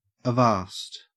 Ääntäminen
Ääntäminen AU RP : IPA : /əˈvɑːst/ IPA : /-ˈvæst/ GA : IPA : /əˈvæst/ Haettu sana löytyi näillä lähdekielillä: englanti Kieli Käännökset suomi seis , riittää Määritelmät Huudahdus Hold fast !; cease !; stop !